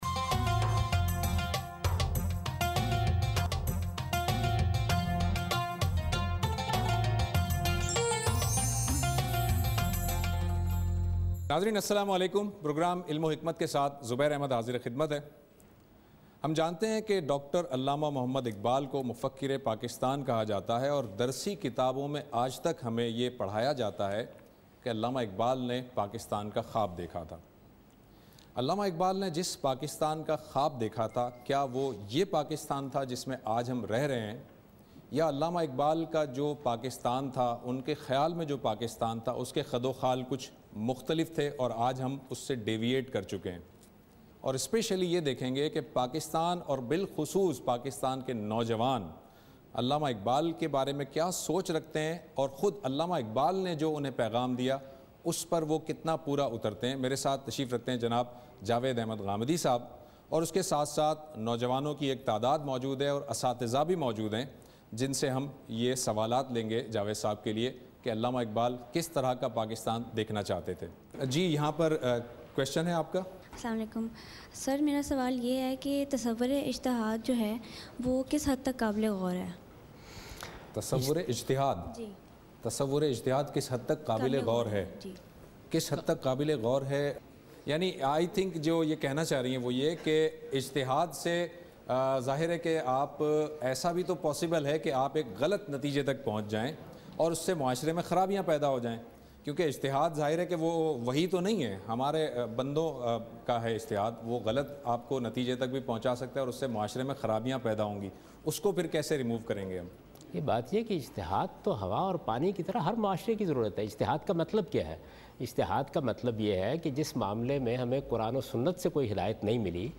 Javed Ahmad Ghamidi speaks and answers questions regarding "Iqbal and Pakistan". (Part-2)